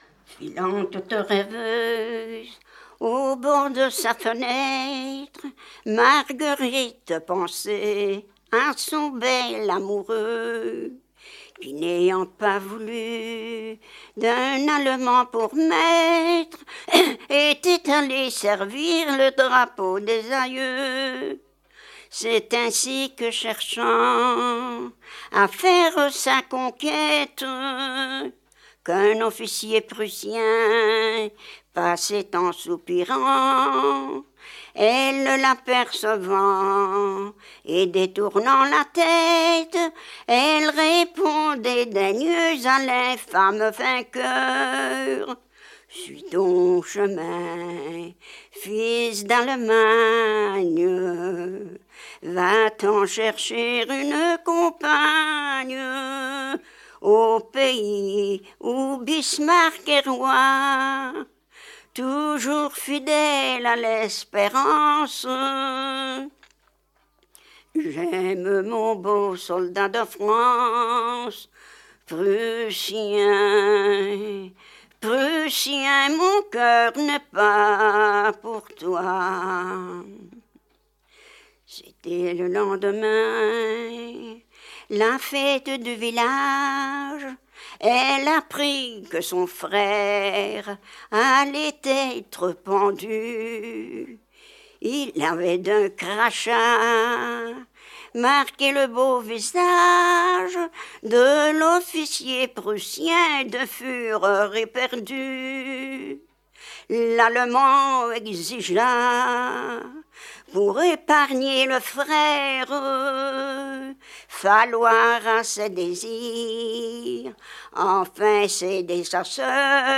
Genre : chant
Type : chanson historique
Support : bande magnétique